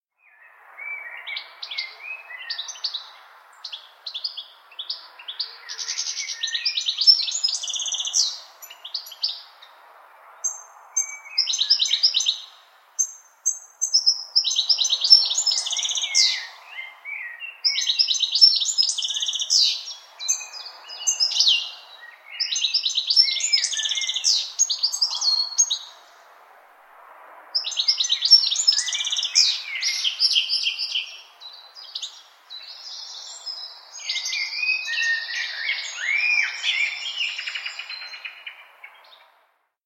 دانلود آهنگ آواز خواندن زیبای پرندگان از افکت صوتی انسان و موجودات زنده
دانلود صدای آواز خواندن زیبای پرندگان از ساعد نیوز با لینک مستقیم و کیفیت بالا
جلوه های صوتی